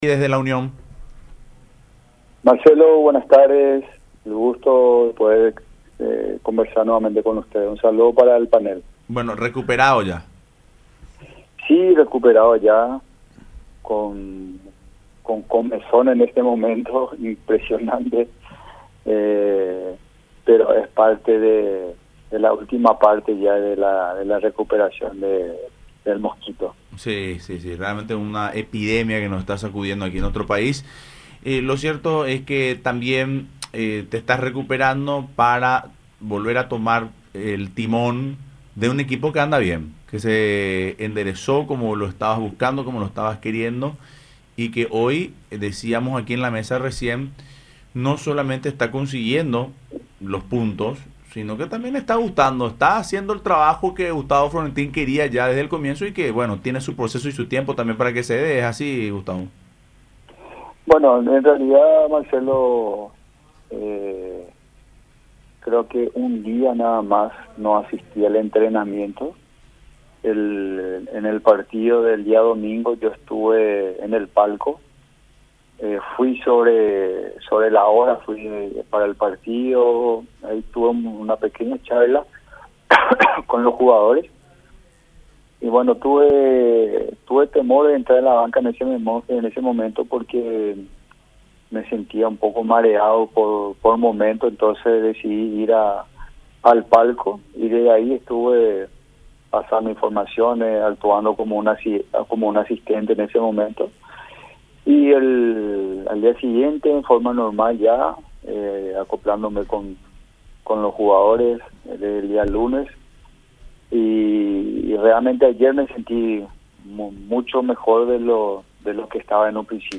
en contracto con Fútbol Club a través de Radio La Unión y Unión TV